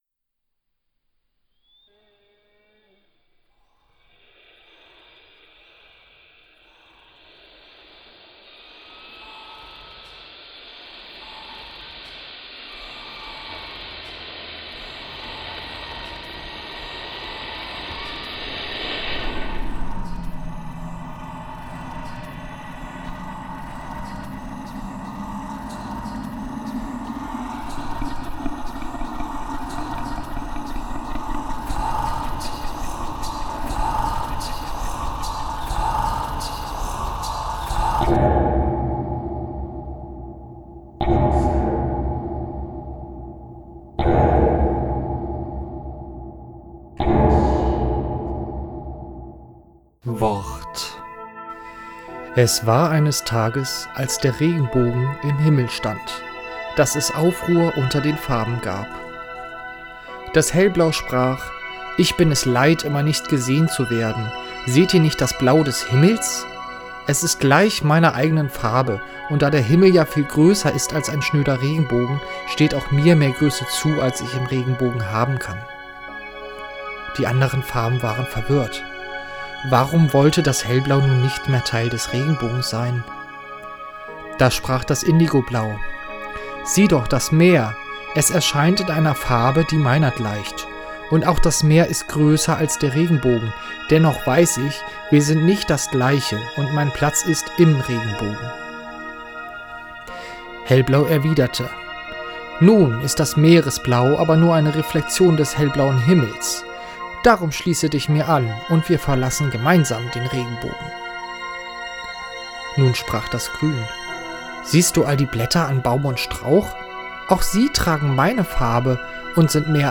Instruments: Vocals, Guitar, Virtual Instruments